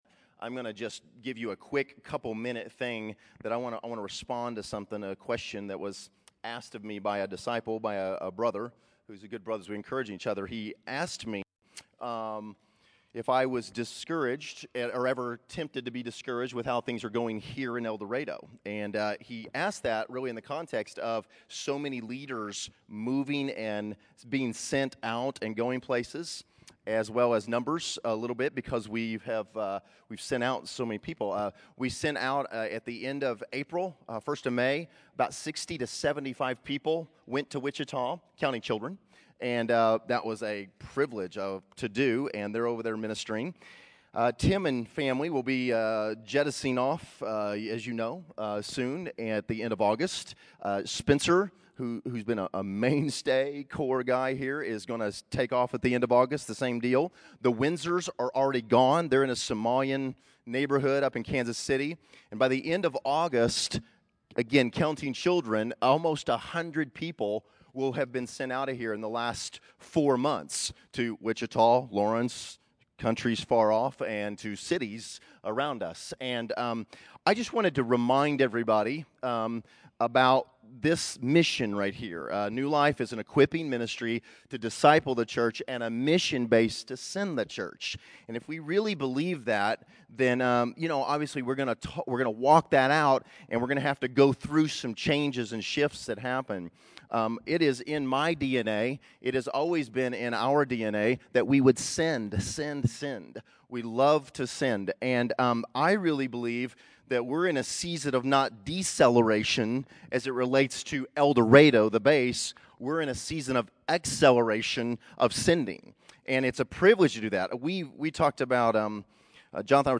Location: El Dorado